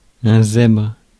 Ääntäminen
Synonyymit oiseau pitre gugusse zigomar Ääntäminen France: IPA: [zɛbʁ] Haettu sana löytyi näillä lähdekielillä: ranska Käännös Substantiivit 1. зебра {f} (zebra) Suku: m .